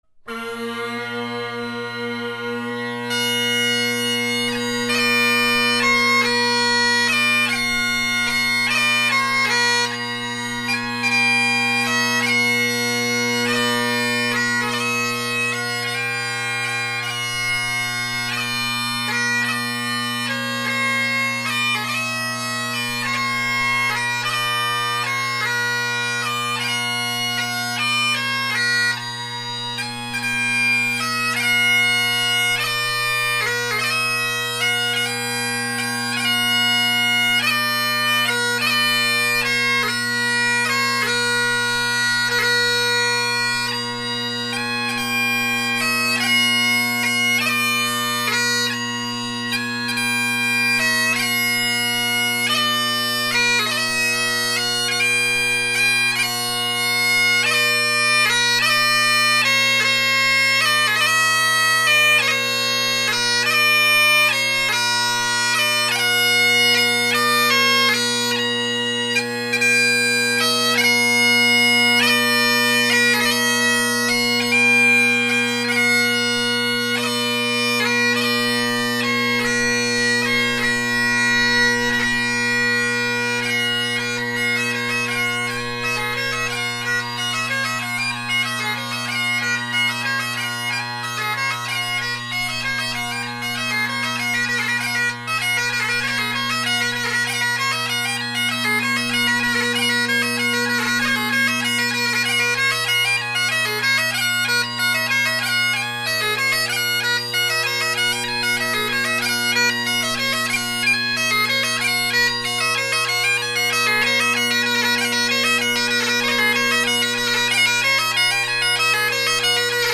2020-09-17: Gibson pipes!
This Gibson chanter is probably ~2004 and has pretty good tone. I notice the E hole is rather large making it a bit sharp and I’m curious if that is specific to this era of Gibson chanter.
Though, as you’ll hear in the recordings, I had a tough time really perfecting how much tape was needed on the E hole.